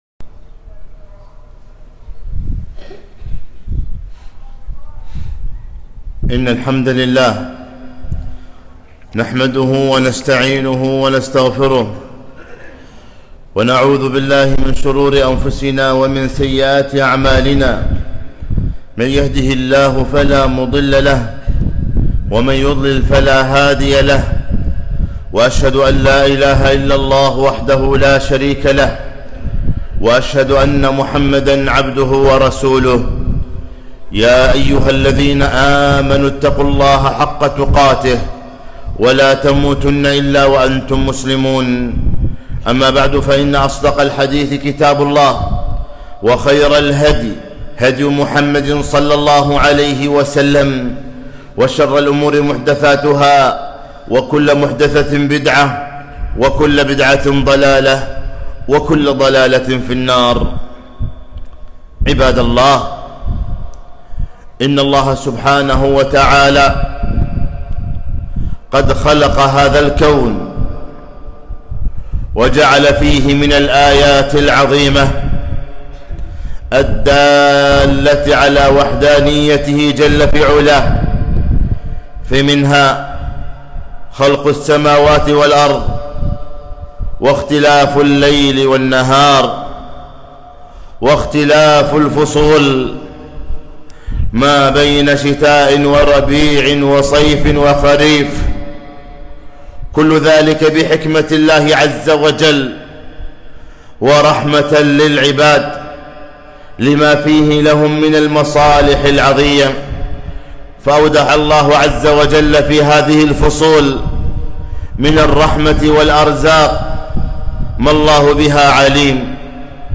خطبة - الشتاء الغنيمة الباردة